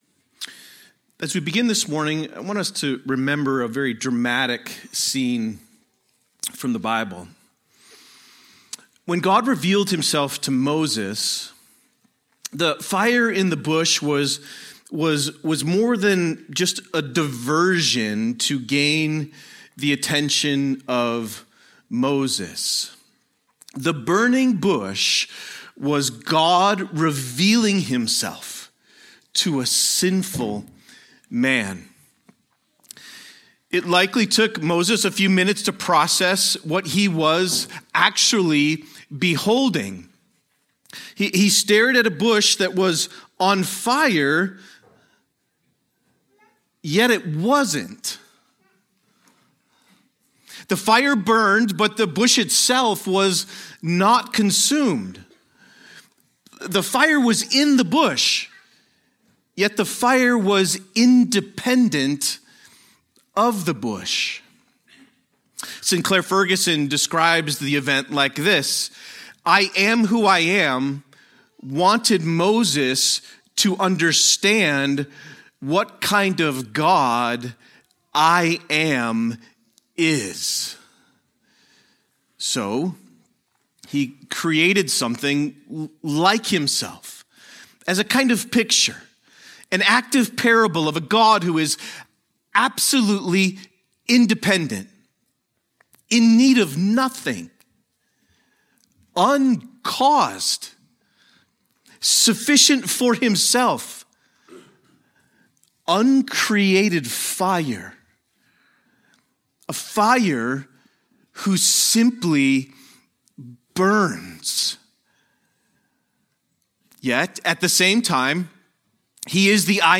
A sermon on James 4:6